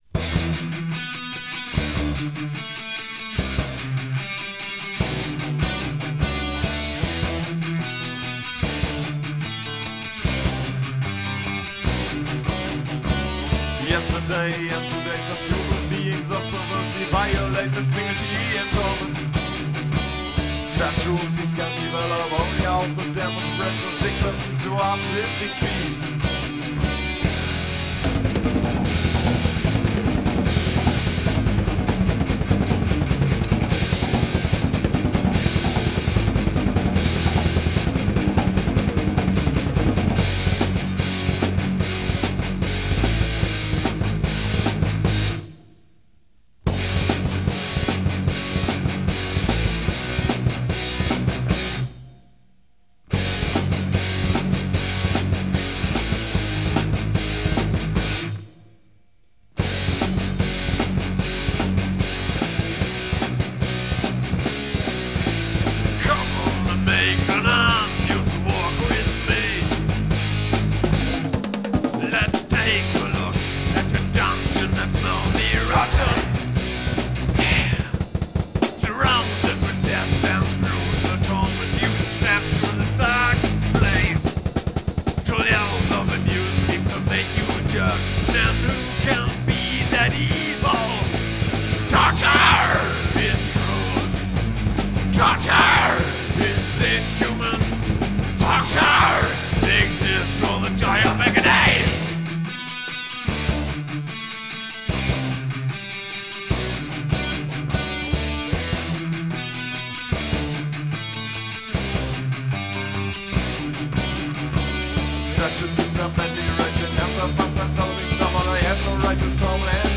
[Demo Tape 1993]